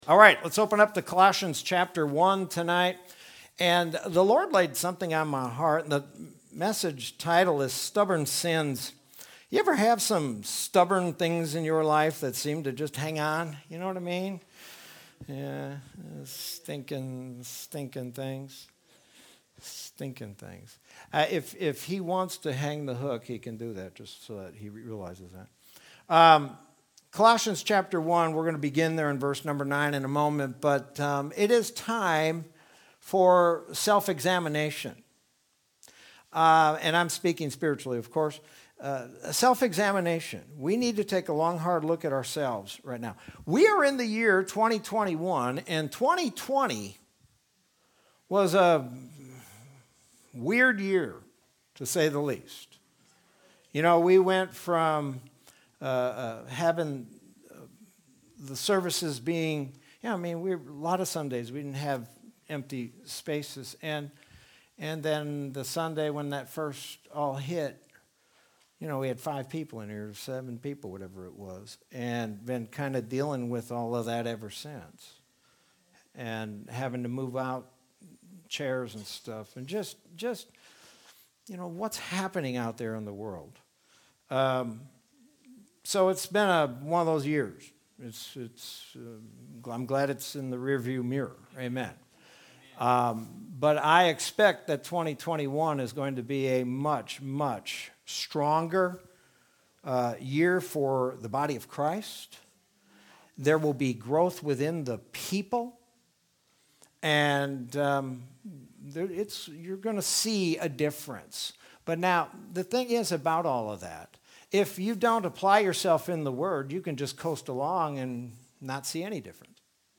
Sermon from Wednesday, February 24th, 2021.